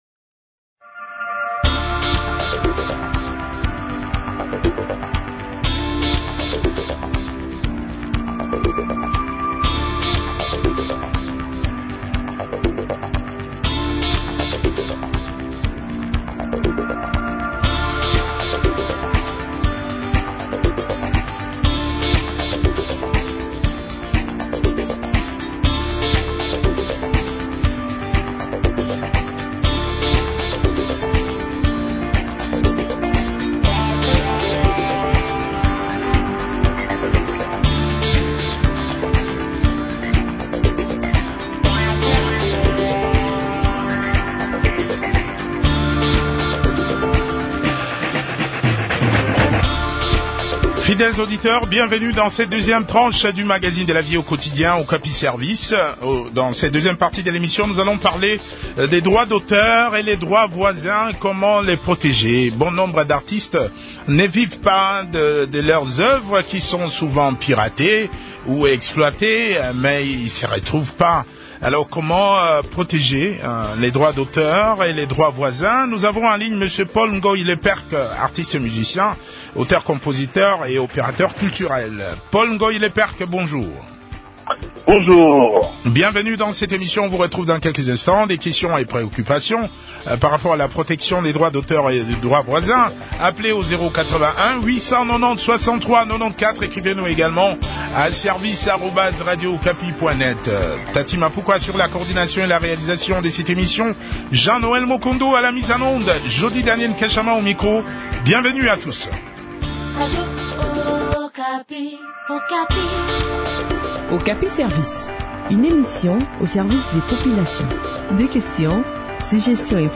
a également pris part à cet entretien.